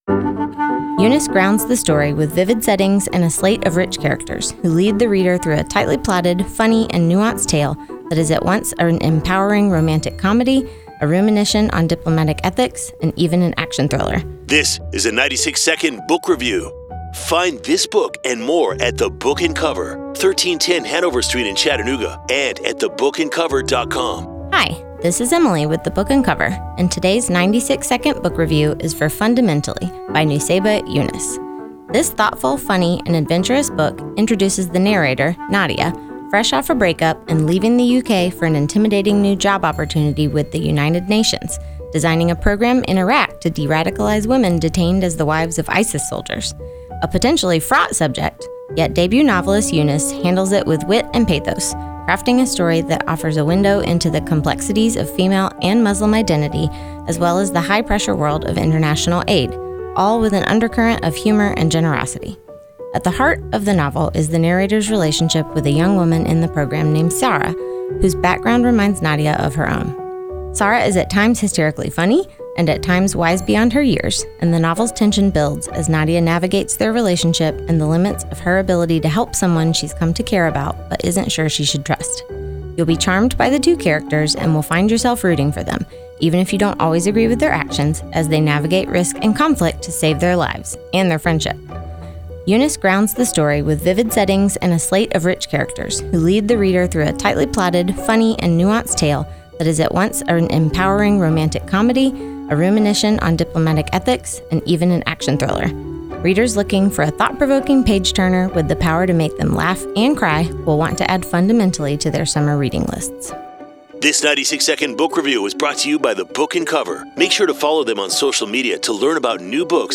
A 96 Book Review: Fundamentally by Nussaibah Younis